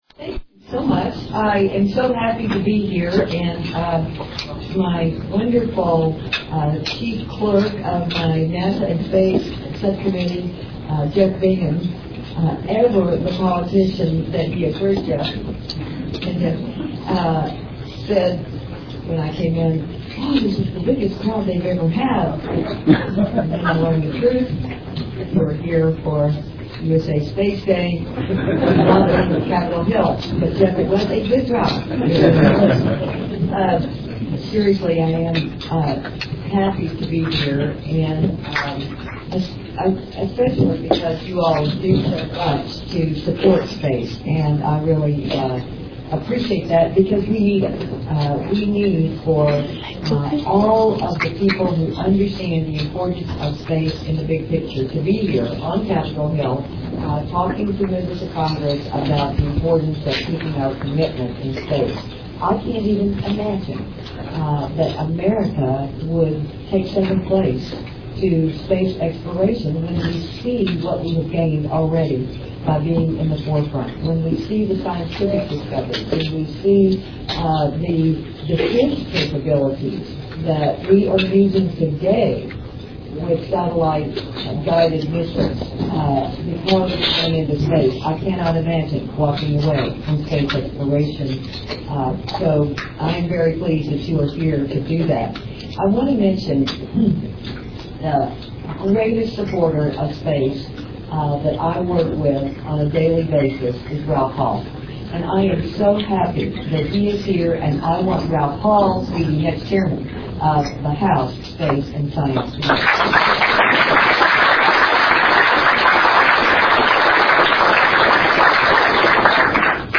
Given the feedback from my last multimedia effort (translation: no one complained), I’ve uploaded the audio from Wednesday’s STA breakfast with Sen. Kay Bailey Hutchison (MP3, 21:03, 2.4 MB).